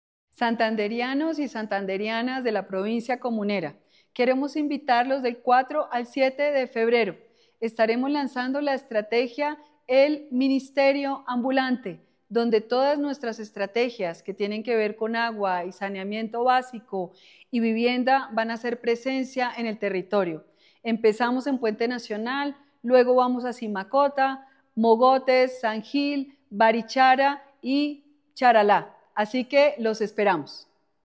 Ruth Quevedo Fique, viceministra de Agua y Saneamiento Básico